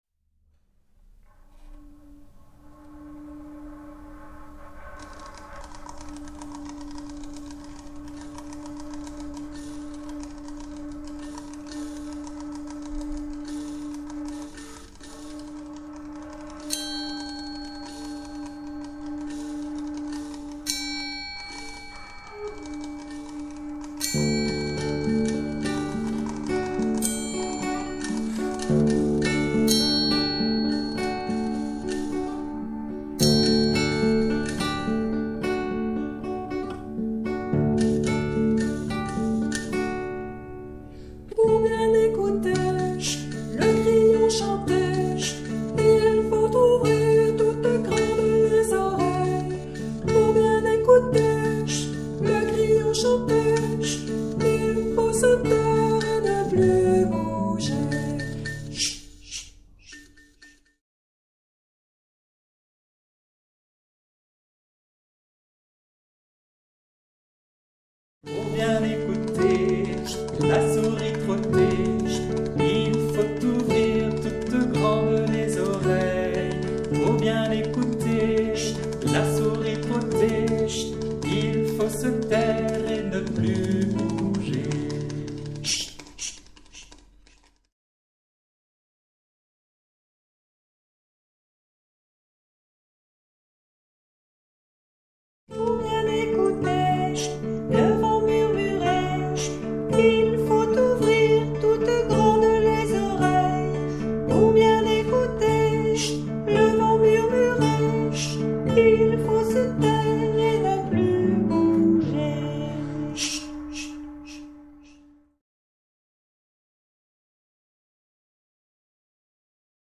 Pour bien écouter - comptine
05_pour_bien_ecouter_silences.mp3